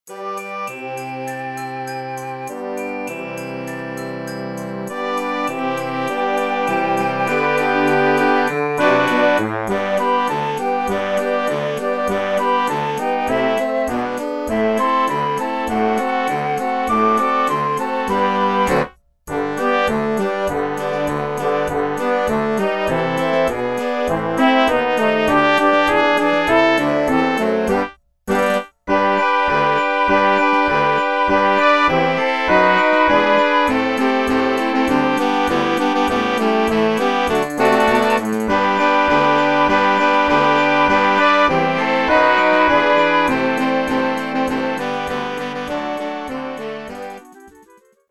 Utwory świąteczne